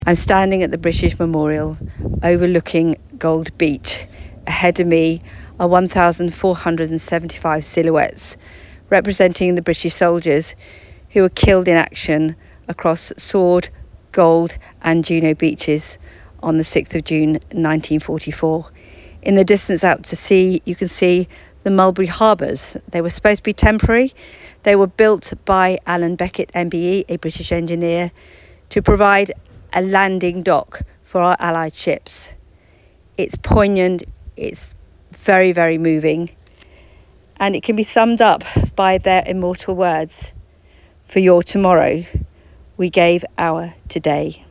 reports from Gold Beach